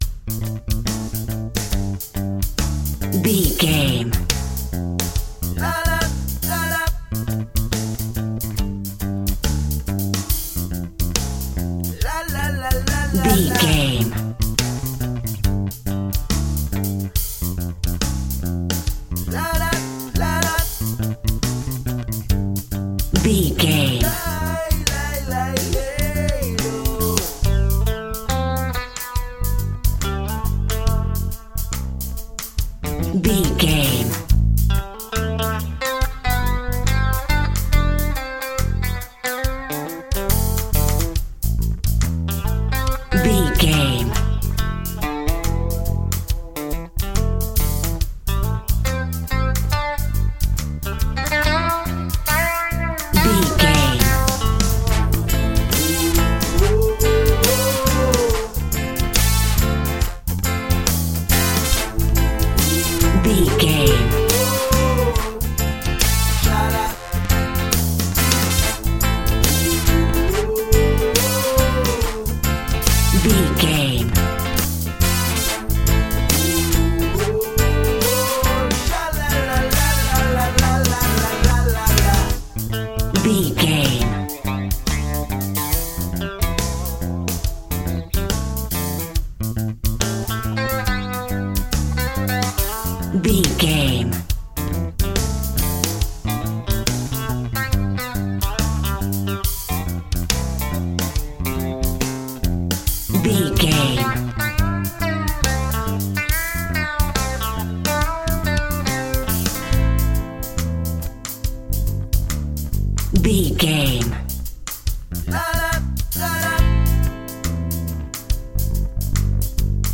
Aeolian/Minor
maracas
percussion spanish guitar